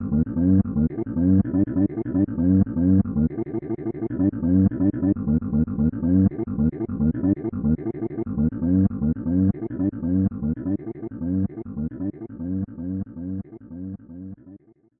Voice clip from Tetris & Dr. Mario
T&DM_Virus_Voice_4.oga.mp3